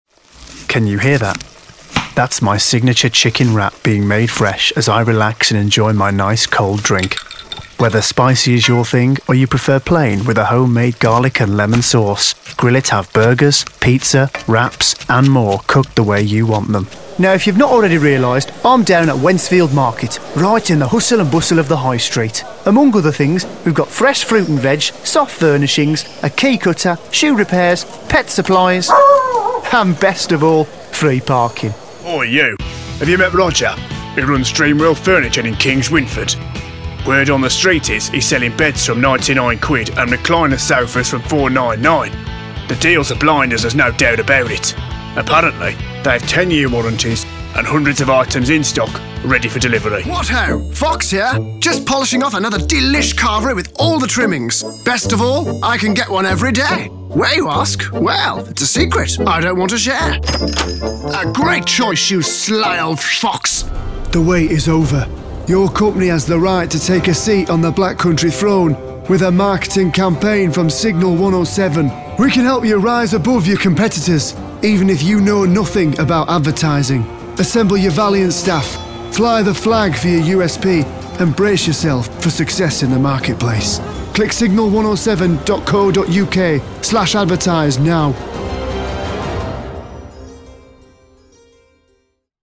Whether it's natural, a character or an impression, from my home studio I'm able to digitally deliver fast turnarounds of broadcast-quality audio in a variety of formats.
VO Commercial Showreel
Commercial-showreel_mixdown.mp3